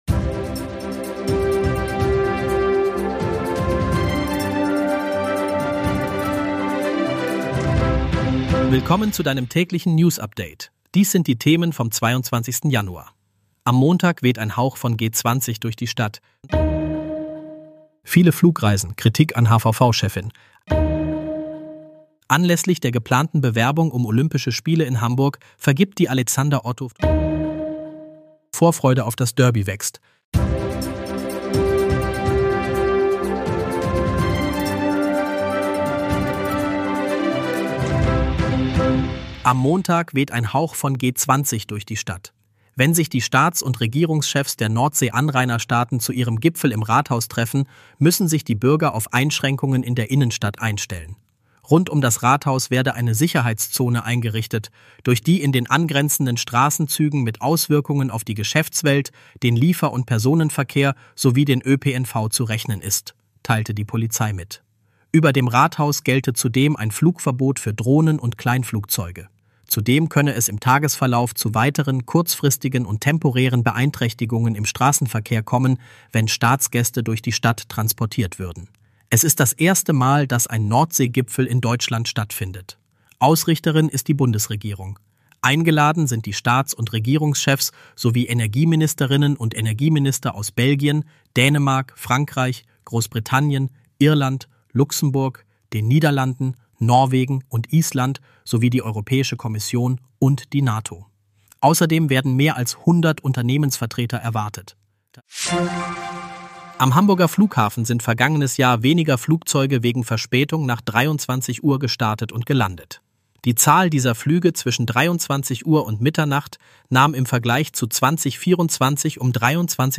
Hamburg-News - der aktuelle Nachrichten-Überblick um 17 h